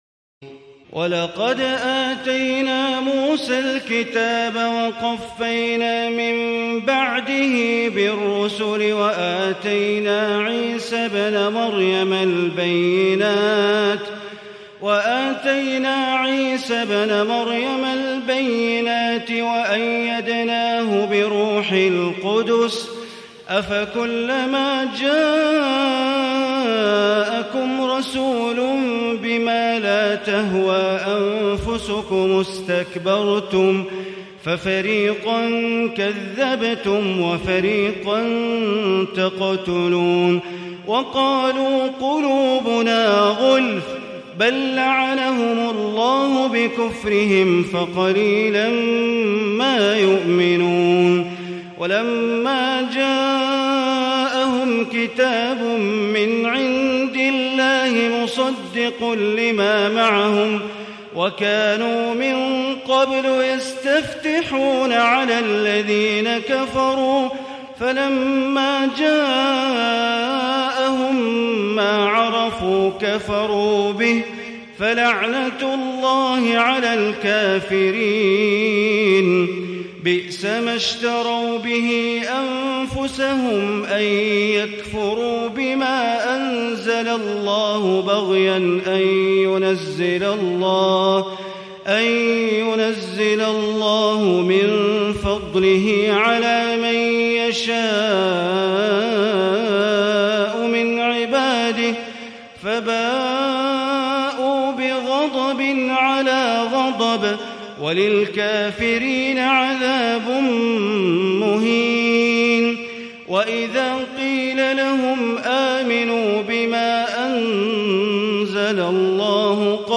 تراويح الليلة الأولى رمضان 1437هـ من سورة البقرة (87-157) Taraweeh 1st night Ramadan 1437 H from Surah Al-Baqara > تراويح الحرم المكي عام 1437 🕋 > التراويح - تلاوات الحرمين